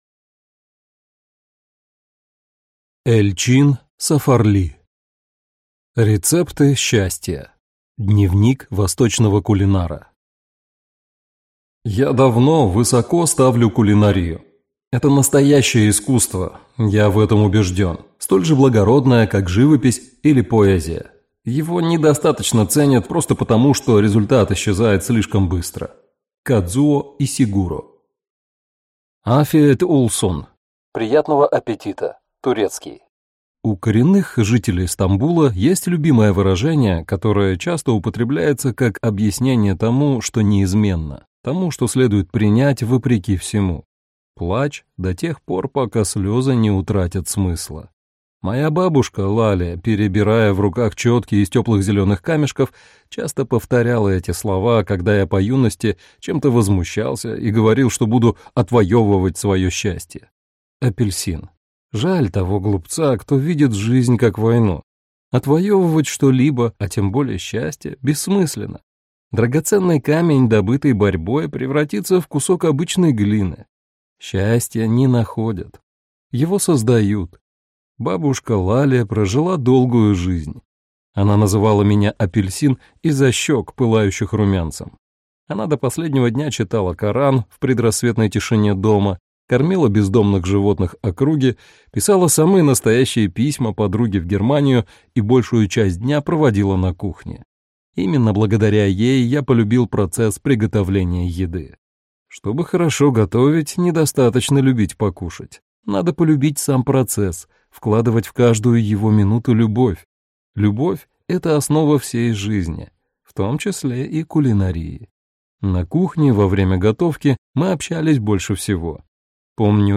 Аудиокнига Рецепты счастья. Дневник восточного кулинара (сборник) | Библиотека аудиокниг